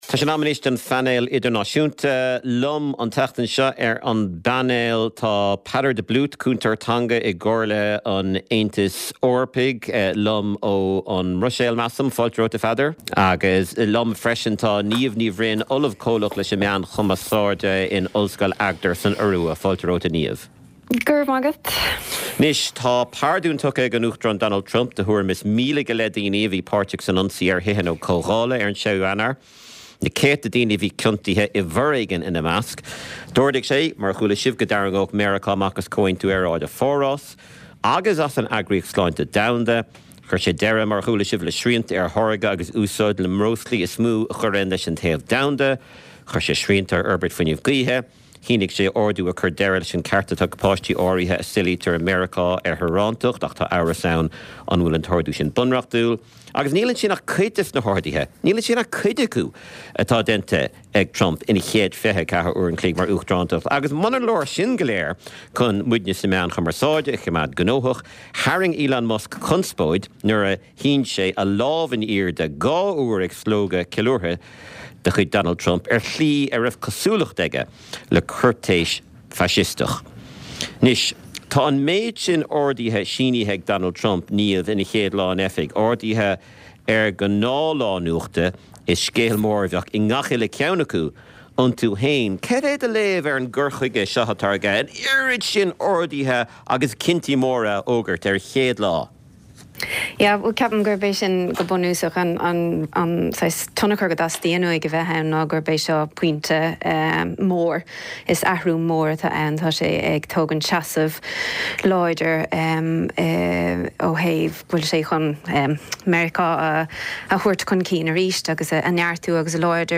Clár cúrsaí reatha an tráthnóna á chur i láthair ón Lárionad Raidió i mBaile Átha Cliath. Scéalta náisiúnta agus idirnáisiúnta a bhíonn faoi chaibidil ar an gclár, le plé, anailís agus tuairiscí.